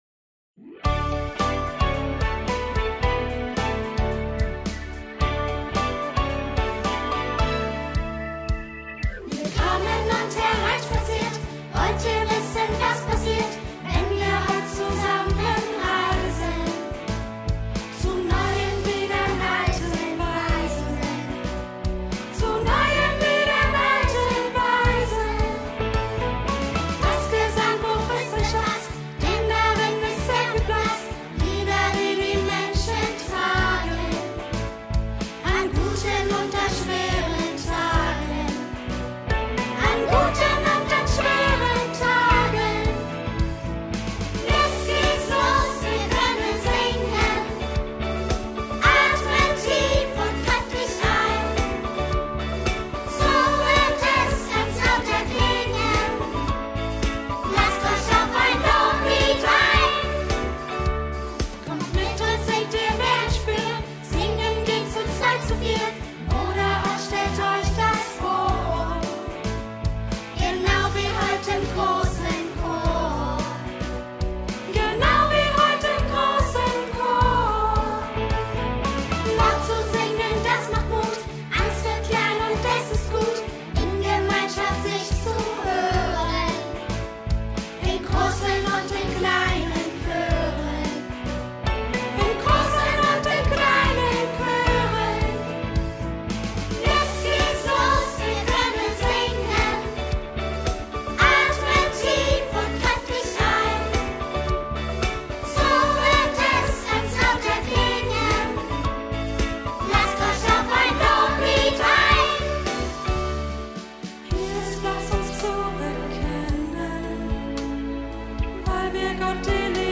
Kinder der Ev. Grundschule
Kinderchorprojekt mit bekannten Gesichtern aus dem Südharz